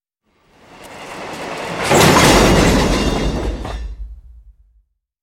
Звук мощного удара падающего воздушного шара с высоты